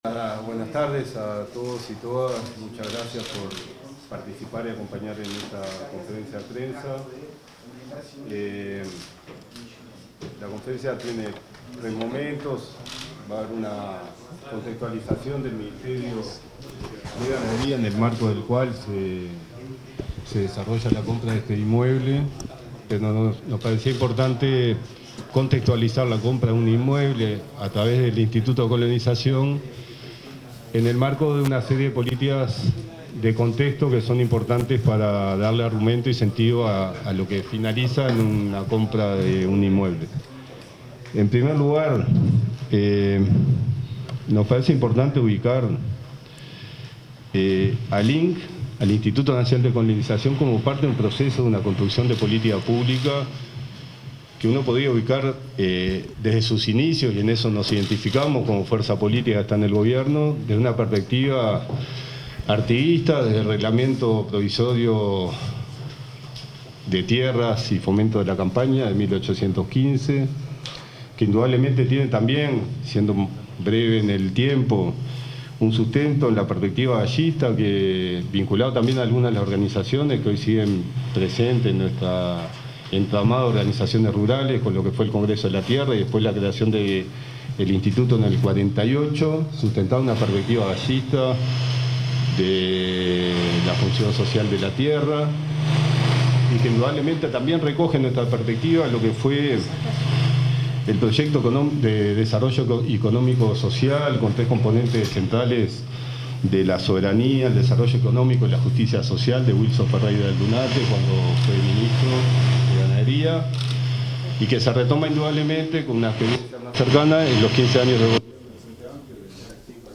Palabras de autoridades del Ministerio de Ganadería y el Instituto de Colonización
Palabras de autoridades del Ministerio de Ganadería y el Instituto de Colonización 20/05/2025 Compartir Facebook X Copiar enlace WhatsApp LinkedIn El ministro interino de Ganadería, Agricultura y Pesca, Matías Carámbula, y el presidente del Instituto Nacional de Colonización, Eduardo Viera, informaron sobre la adquisición de un inmueble rural ubicado en el departamento de Florida, orientada a responder la demanda de tierras del sector lechero familiar.
conferencia-mgap.mp3